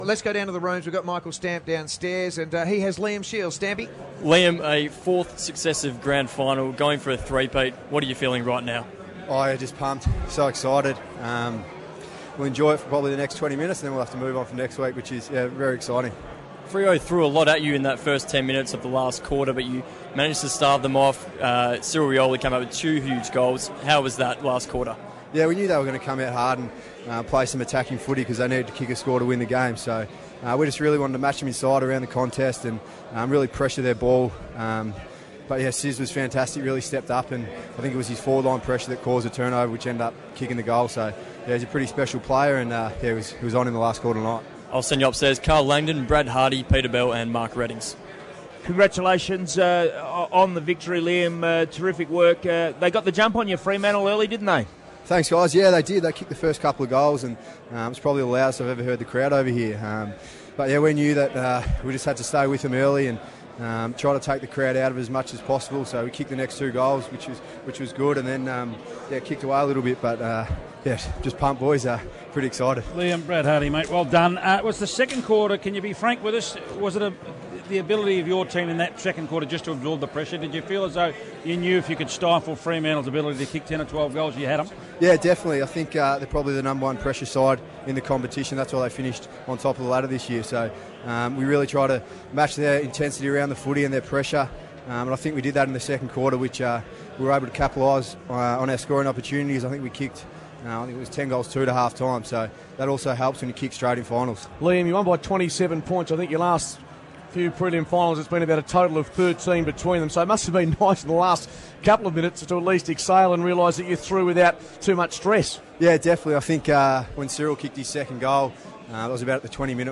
Liam Shiels Post Game Interview